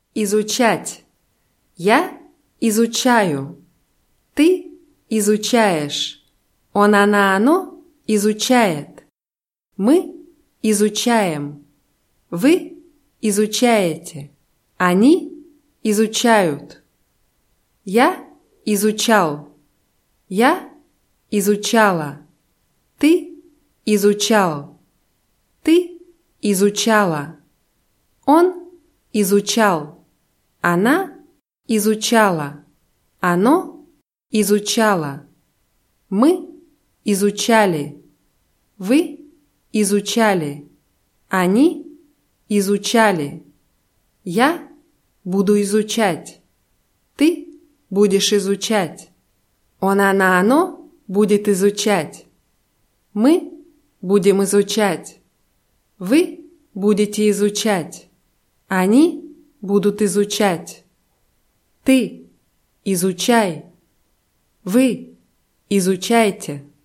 изучать [izutschátʲ]